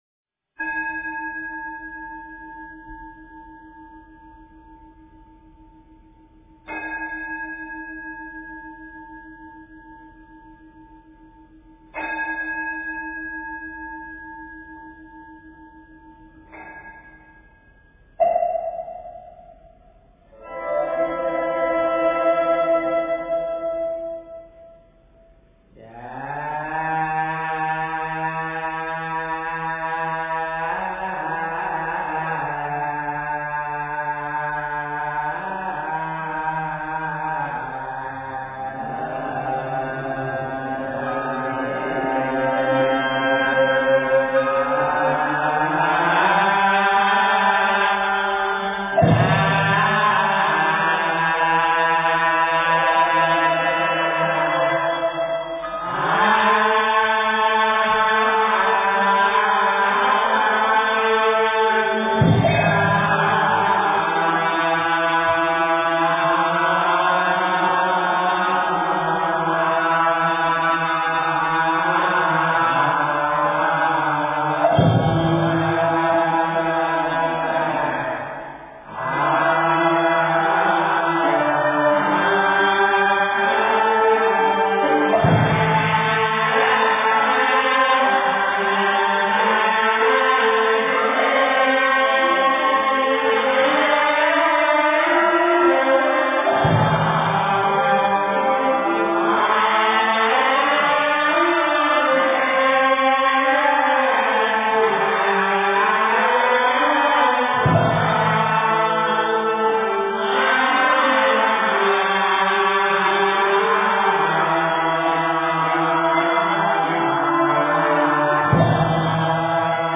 戒定真香-1--僧团 经忏 戒定真香-1--僧团 点我： 标签: 佛音 经忏 佛教音乐 返回列表 上一篇： 早课--临济宗净觉山光德寺 下一篇： 杨枝净水赞--佛音 相关文章 千年之悦--风潮唱片 千年之悦--风潮唱片... 85.菩萨的作为--佚名 85.菩萨的作为--佚名...